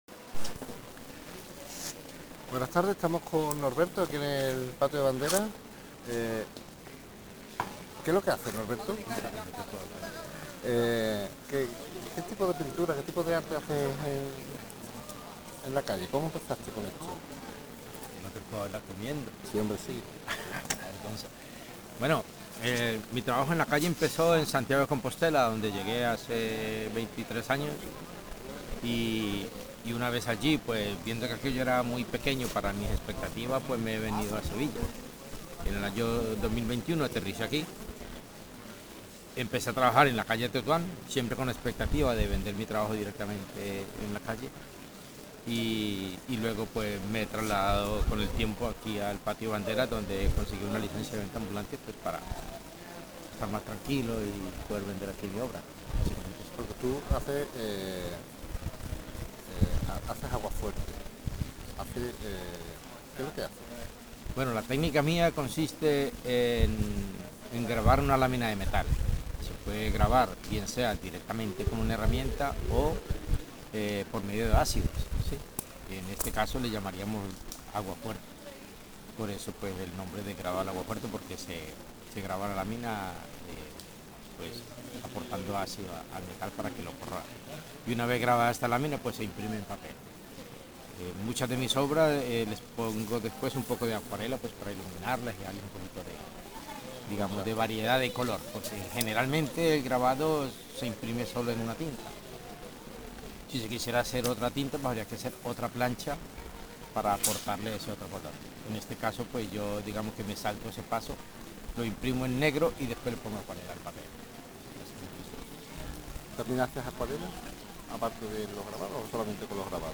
Audio entrevista                              Transcripción entrevista